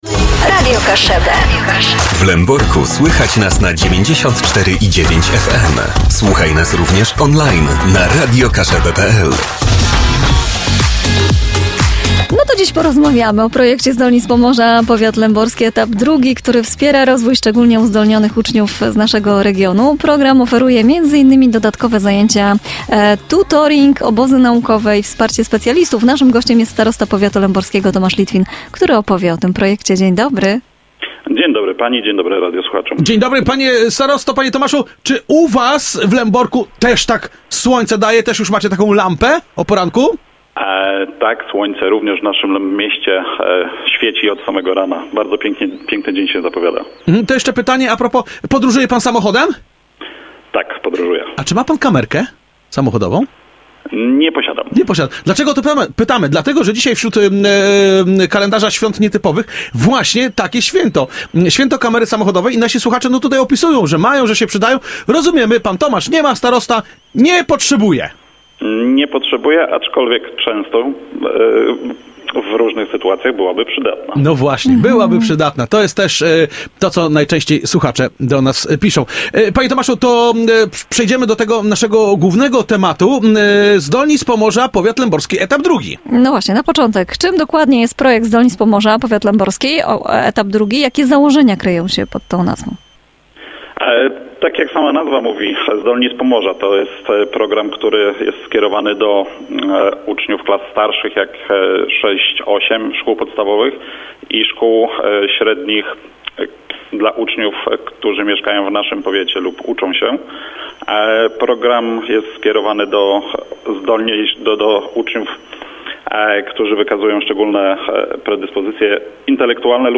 rozmowa_Tomasz-Litwin-Starosta-powiatu-leborskiego.mp3